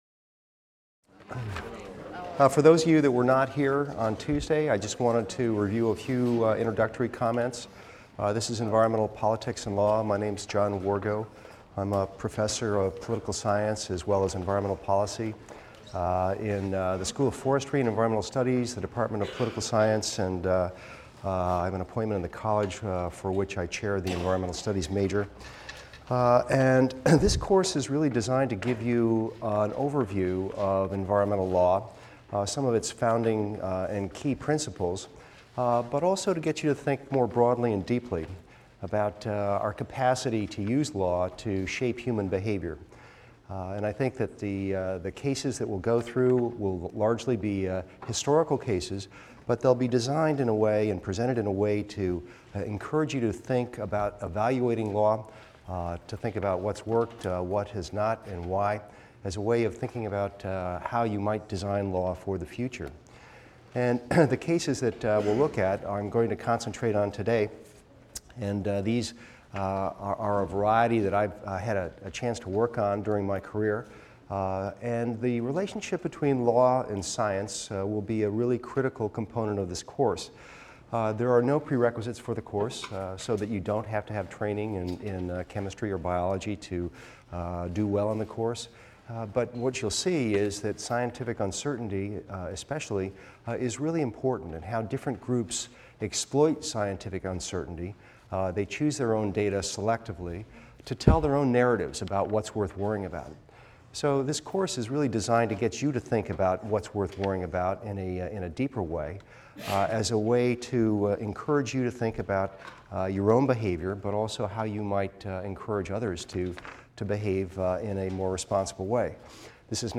EVST 255 - Lecture 2 - Principles and Strategies in Environmental Law | Open Yale Courses